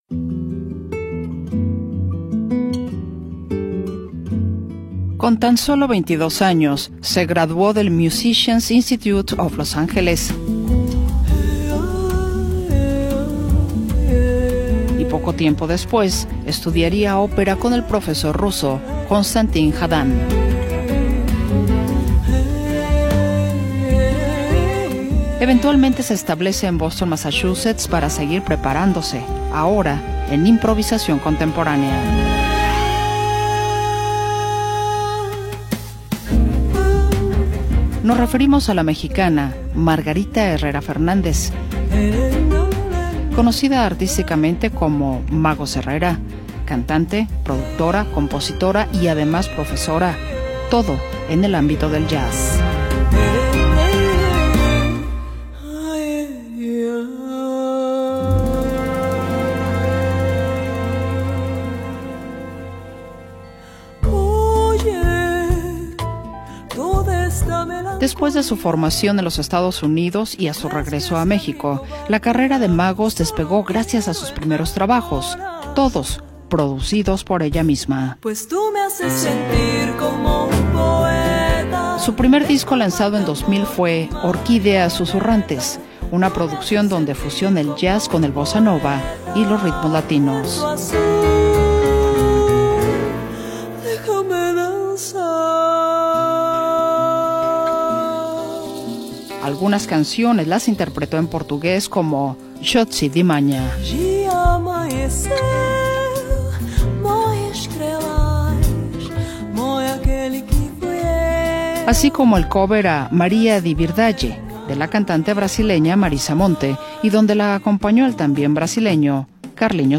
compositora y productora mexicana de jazz latino